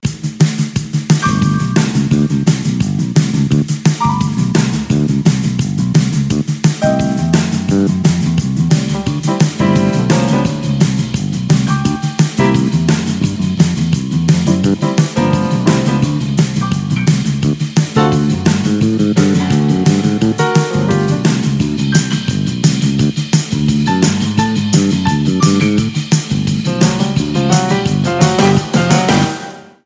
Soundtrack.